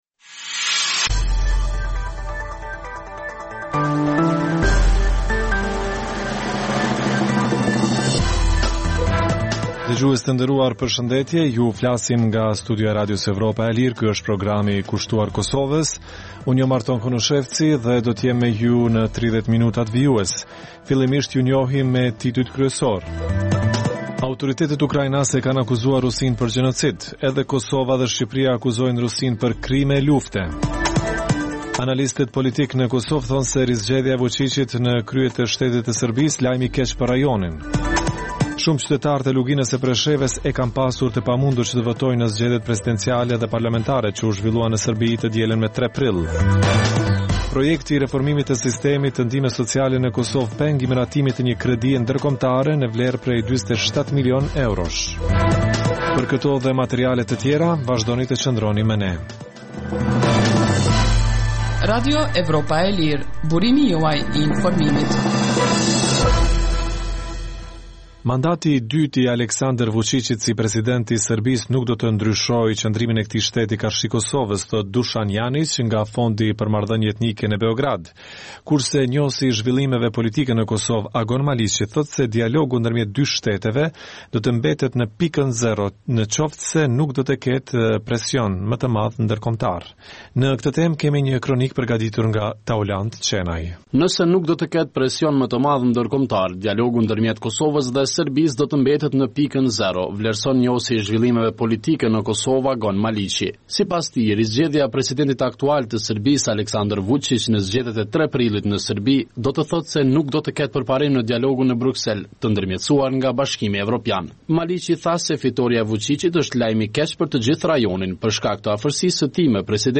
Emisioni i orës 16:00 është rrumbullaksim i zhvillimeve ditore në Kosovë, rajon dhe botë. Rëndom fillon me kronikat nga Kosova dhe rajoni, dhe vazhdon me lajmet nga bota. Kohë pas kohe, në këtë edicion sjellim intervista me analistë vendorë dhe ndërkombëtarë për zhvillimet në Kosovë.